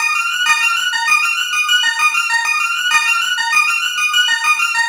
Rock Star - Wind Chime.wav